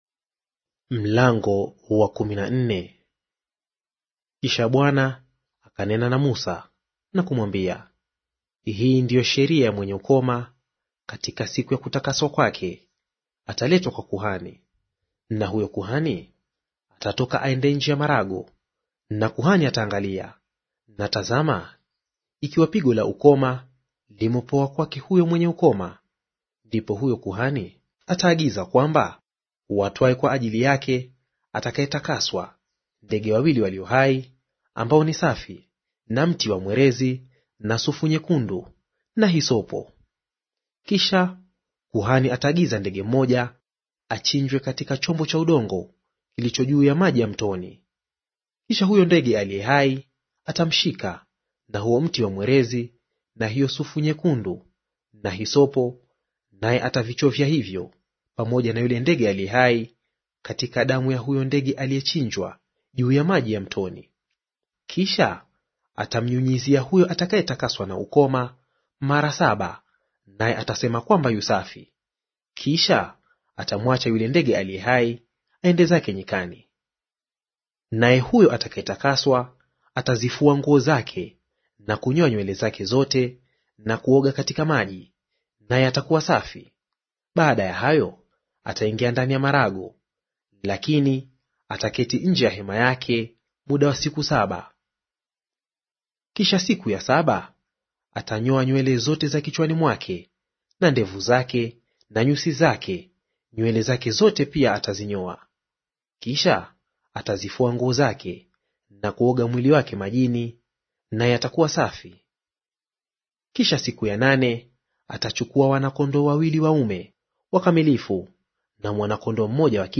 Audio reading of Mambo ya Walawi Chapter 14 in Swahili